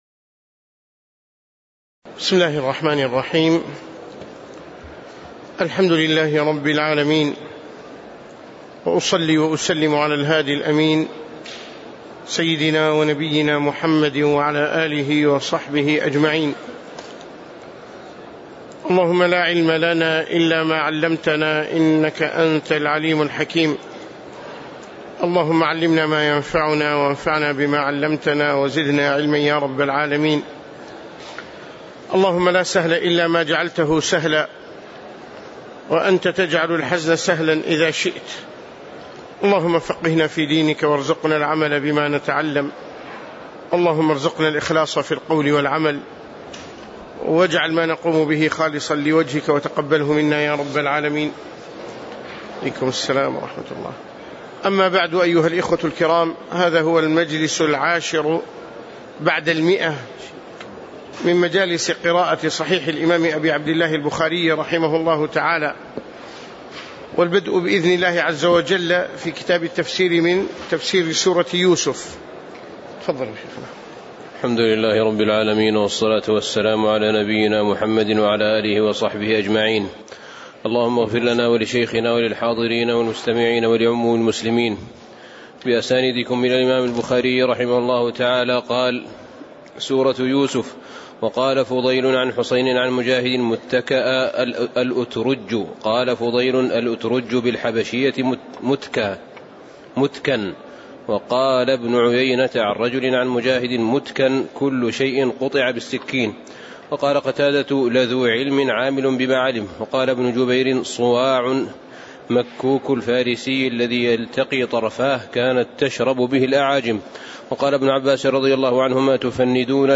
تاريخ النشر ٤ شعبان ١٤٣٨ هـ المكان: المسجد النبوي الشيخ